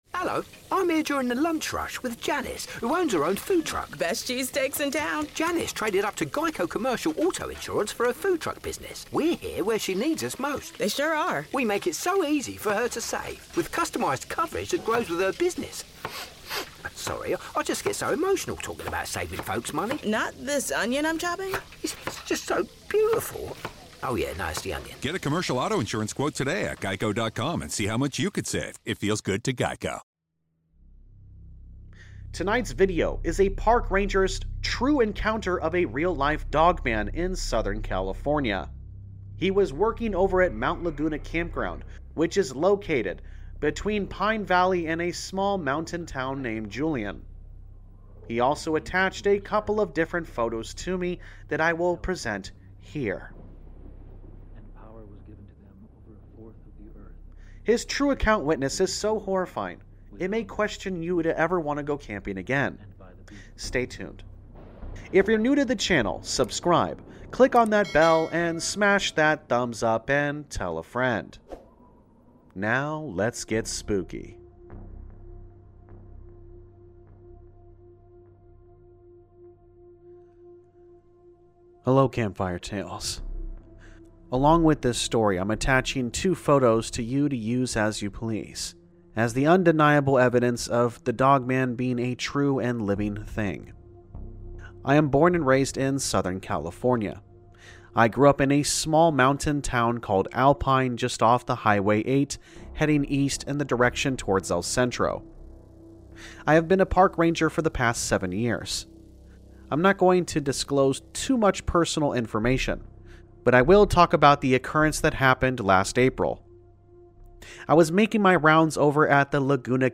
All Stories are read with full permission from the authors: Story Credit - anonymous